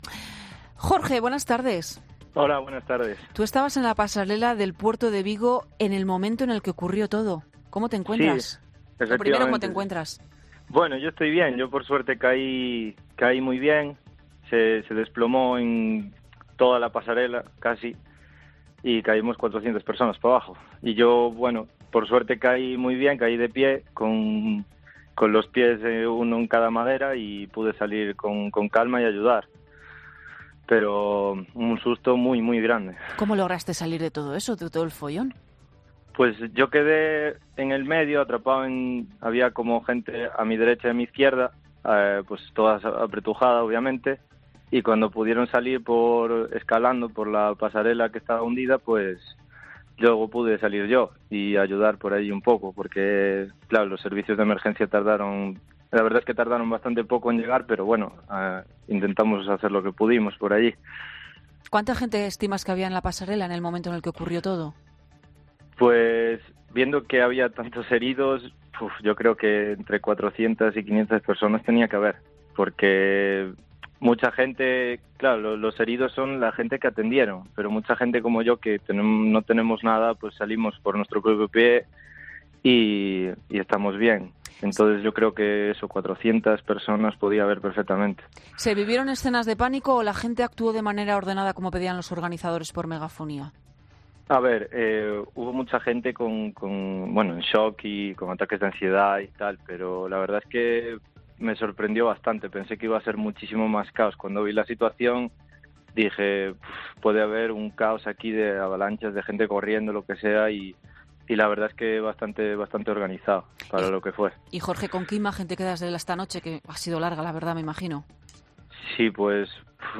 Una víctima y un testigo del accidente de Vigo relatan su experiencia en 'Mediodía COPE'